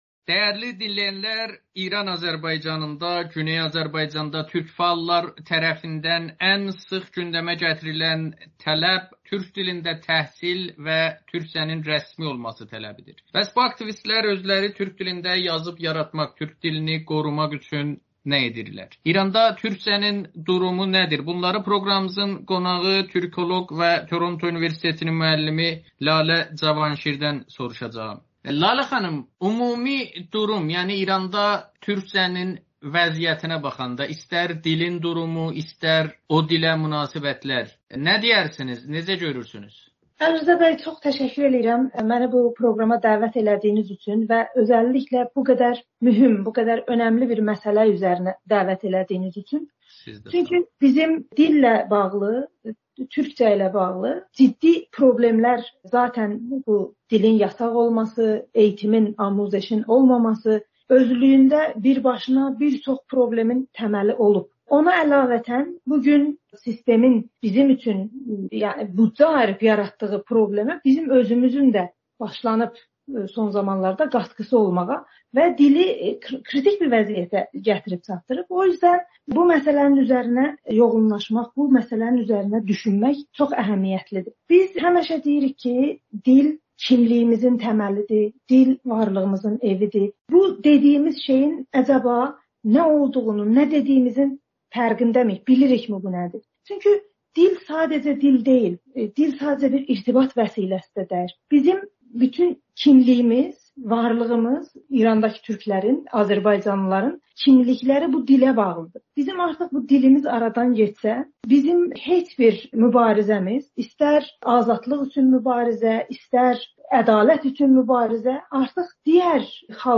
Amerikanın Səsinə müsahibədə İran Azərbaycanında türk dili və ədəbiyyatının hazırkı vəziyyətini dəyərləndirib.